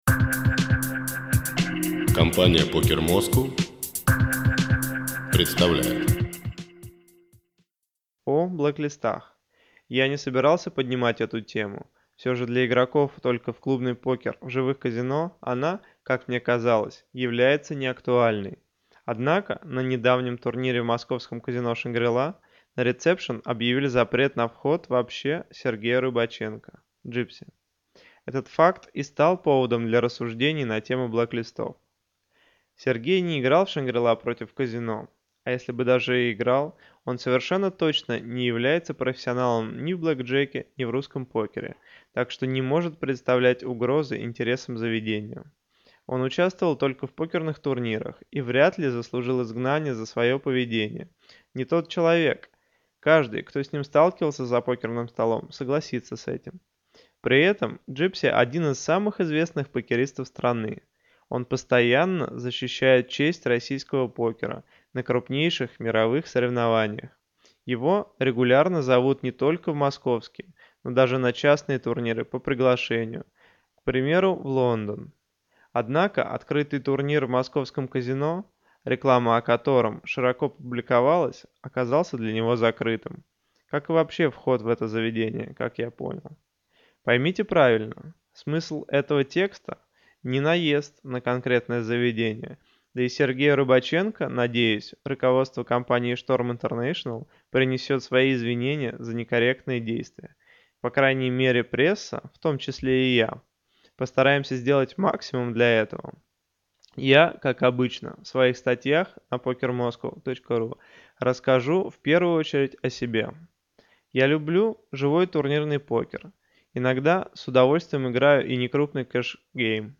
Аудио-версия статьи «О блэклистах»: